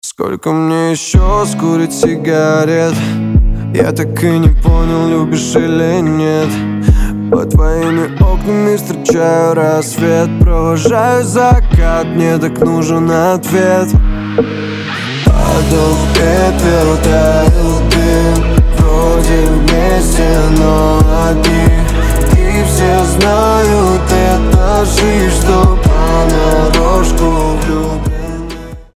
гитара
лирика
красивый мужской голос